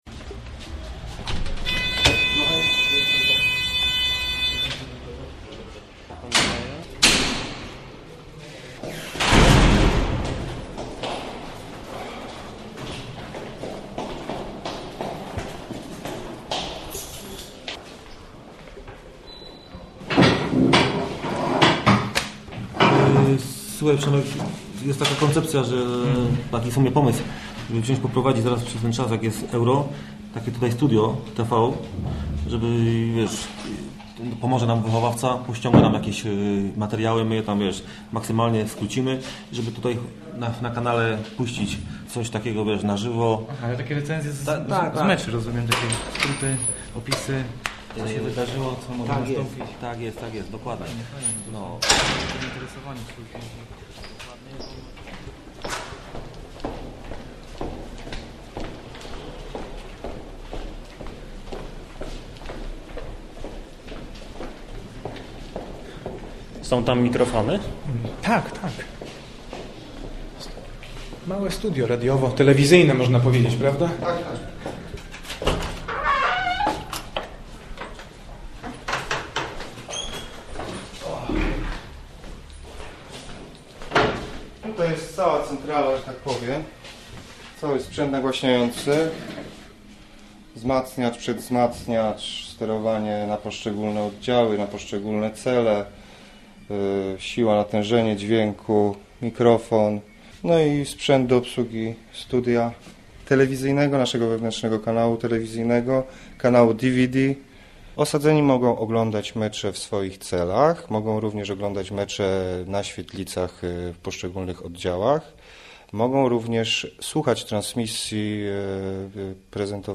Skazani na radio - reportaż